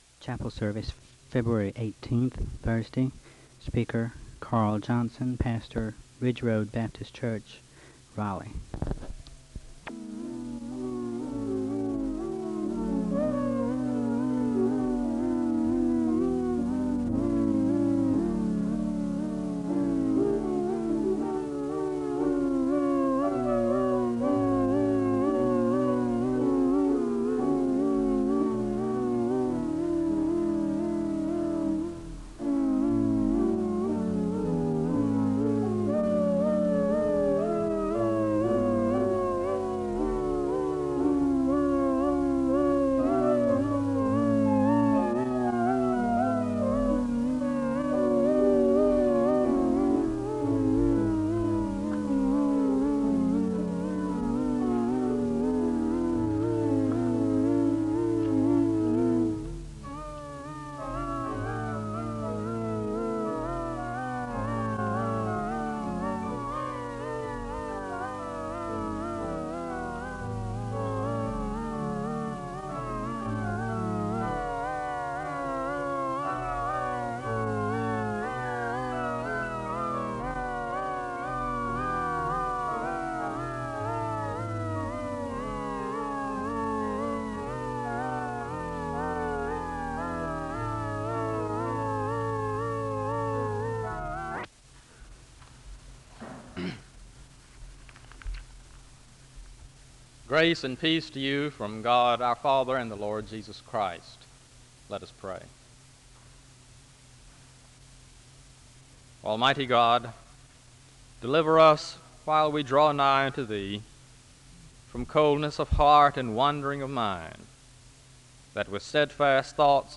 The service begins with organ music (00:00-01:35).
The speaker gives a word of prayer, and he introduces the hymn (01:36-02:23). The speaker gives another word of prayer (02:24-04:43).
The choir sings a song of worship (06:31-08:55).
Location Wake Forest (N.C.)